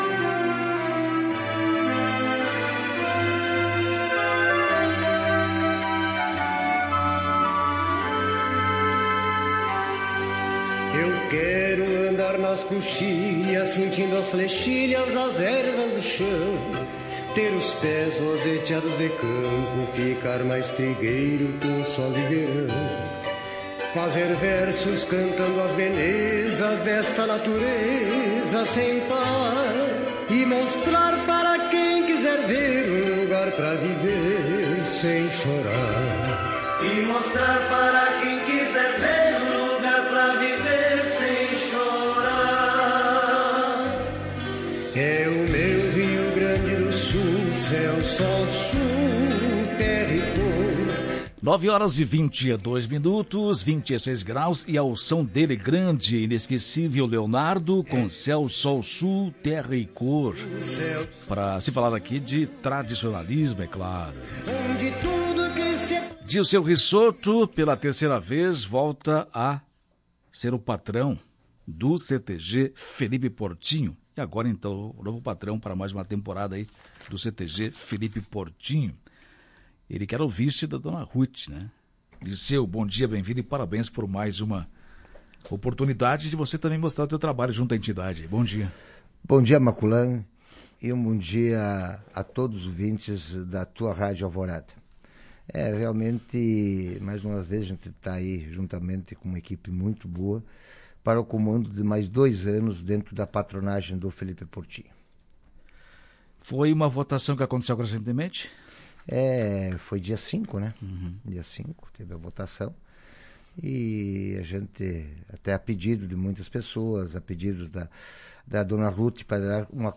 Em entrevista para a Tua Rádio Alvorada